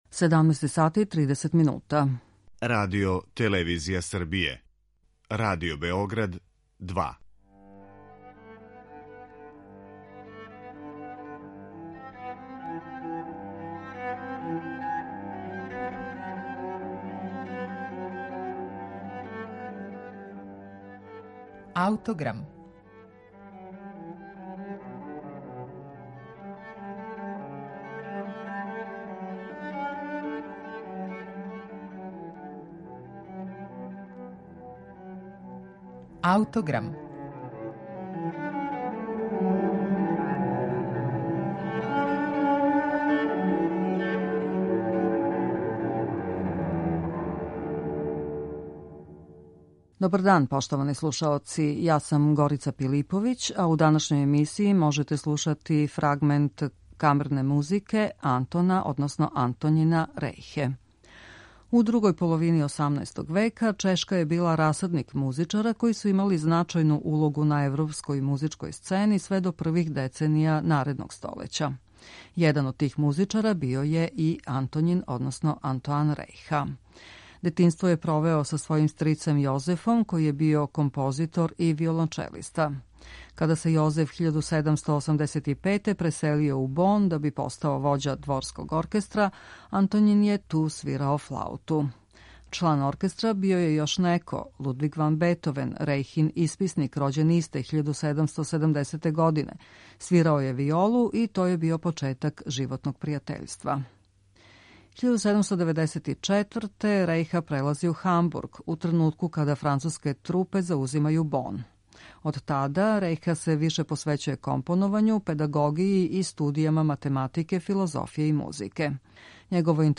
Велики квинтет са фаготом
Бројна су дела у којима су поједини дувачки инструменти удружени са гудачким квартетом, попут вечерашњег квинтета са фаготом.